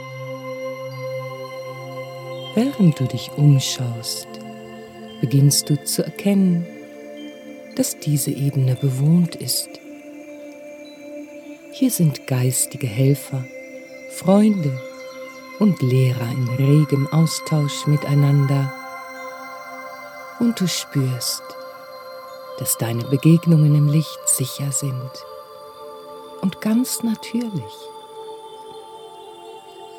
Durch die fein abgestimmte Kombination aus echten Naturklängen und heilsamen sphärischen Melodien erreichen Sie eine Veränderung der wissenschaftlich belegten Schwingungen in Ihrem Gehirn - von Beta-Wellen (38-15 Hz) zu Alpha-Wellen (14-8 Hz) hin zu Theta-Wellen (7-4 Hz).
Für einen optimalen Effekt empfehlen wir das Hören über Kopfhörer.